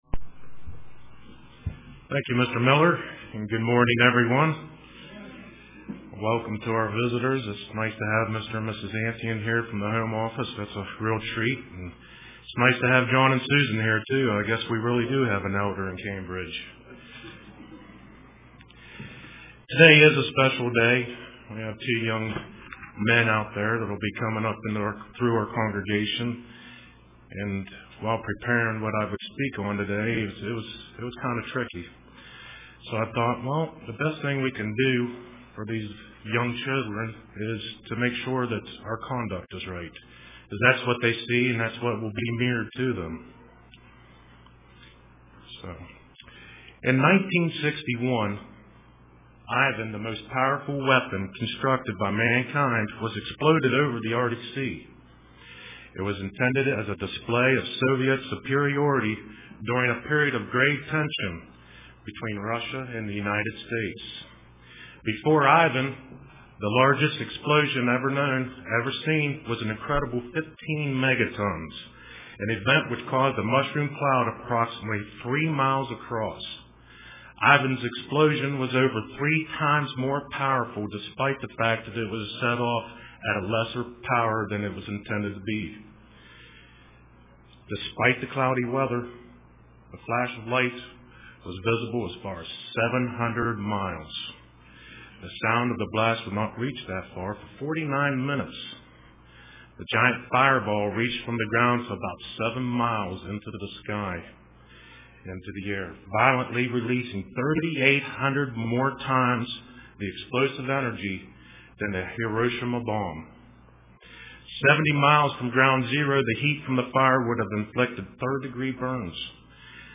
Print Think Before We Speak UCG Sermon Studying the bible?